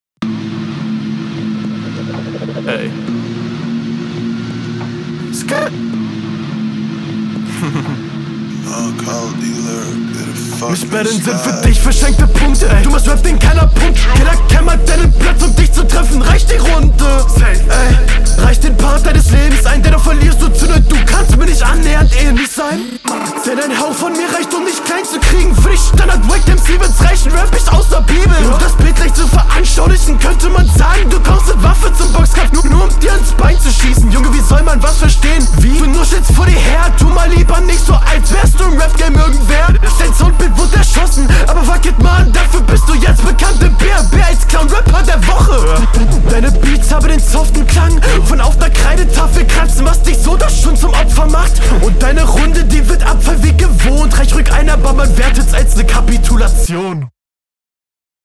flow immer sehr nice gedacht, aber noch nicht wirklich locker rübergebracht, bissl entspannter werden, dann …
Schöne Lines, geiler Stimmensatz, nicer Beat.